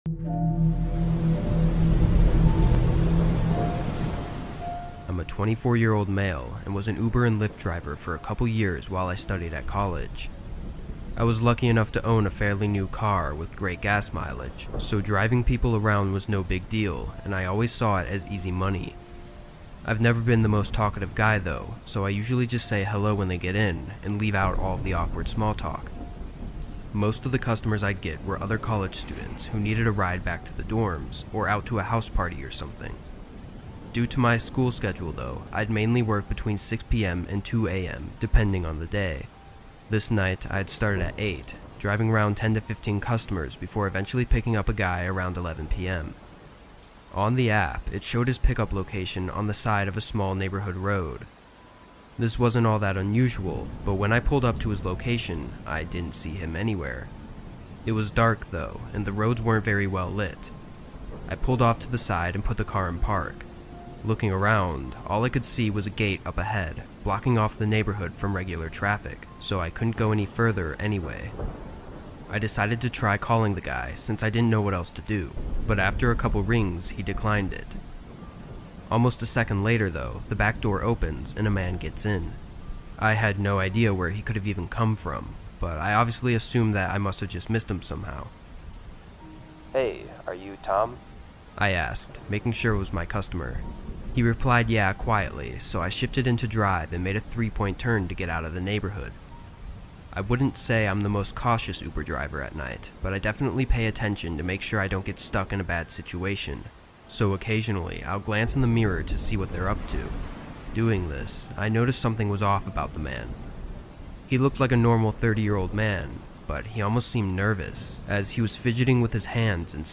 Rainy Night Driving True Horror Stories | With Rain Sounds That Will Leave You Paralyzed